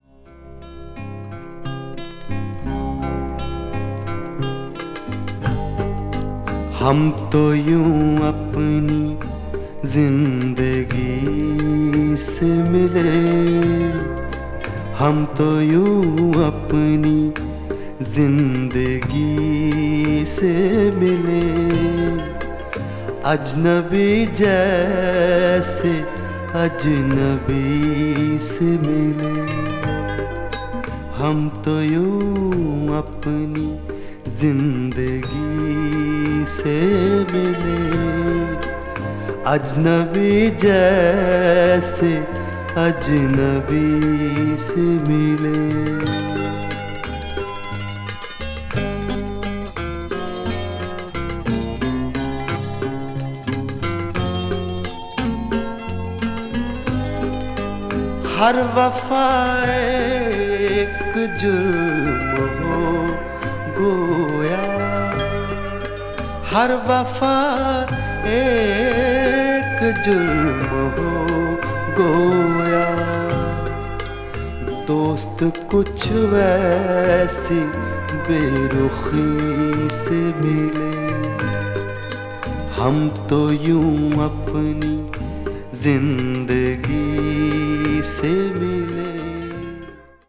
がざる和音や旋律は日本の歌謡曲にも近似するところがあるので、とても耳馴染みが良いです。
ghazal   india   indian pop   mellow groove   world music